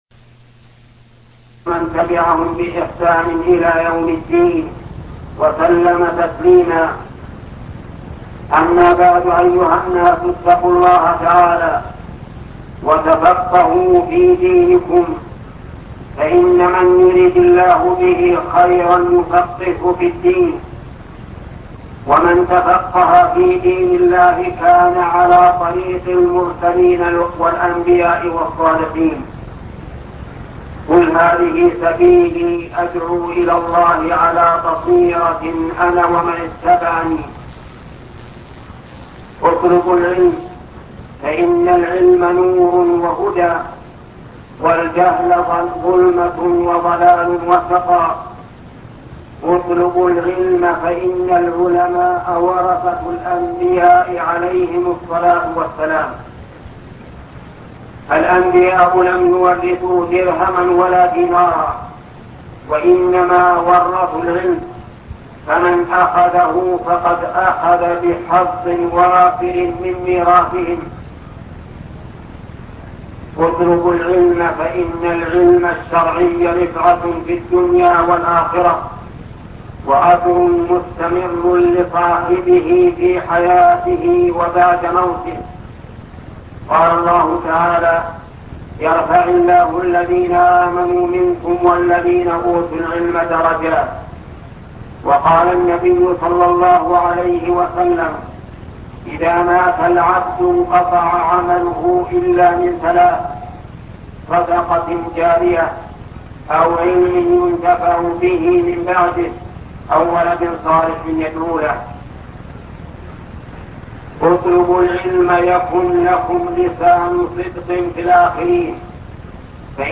خطبة صفة الحج الشيخ محمد بن صالح العثيمين